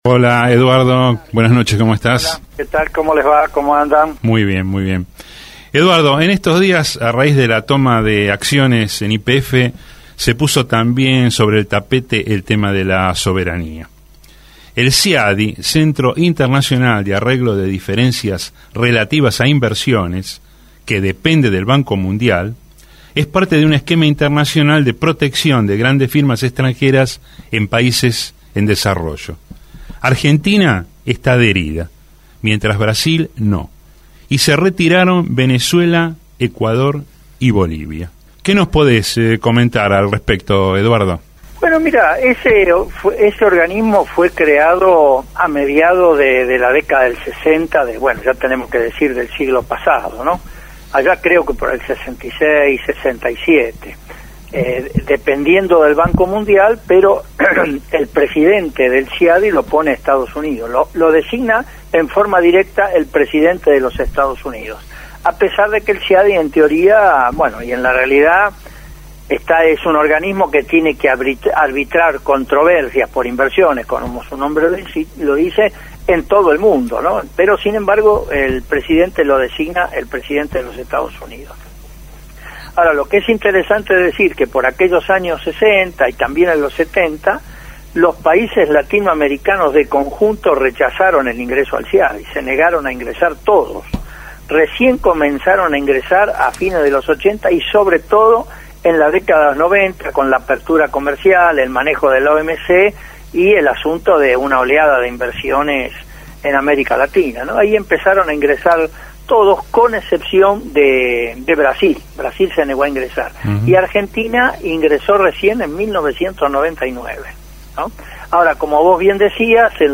economista, habló en el programa Tengo una Idea.